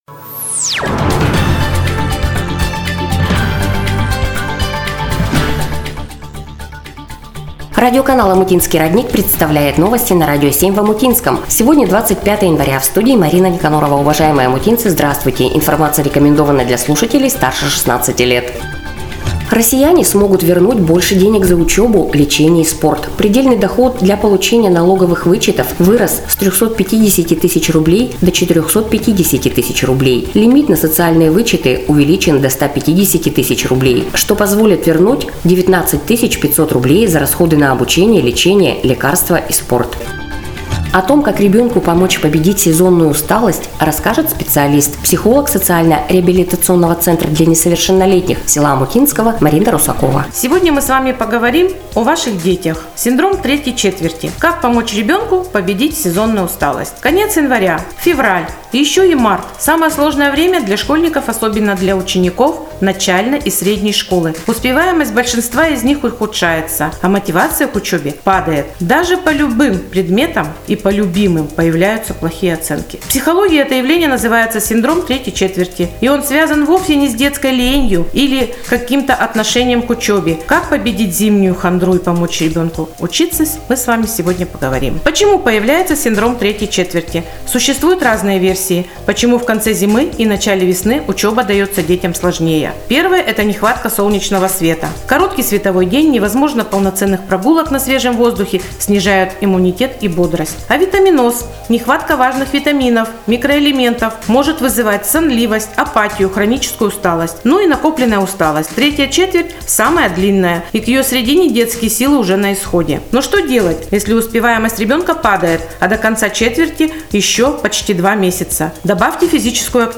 Эфир радиоканала "Омутинский родник" от 25 Января 2025 года
NOVOSTI-25.01.25.mp3